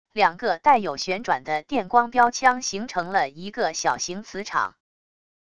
两个带有旋转的电光标枪形成了一个小型磁场wav音频